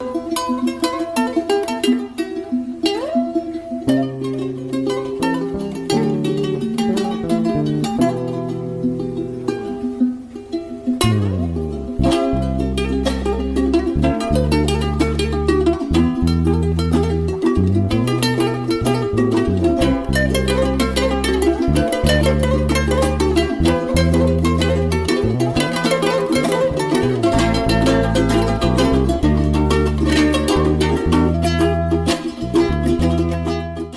Truely melancholious!!!